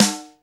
HR16B SNR 04.wav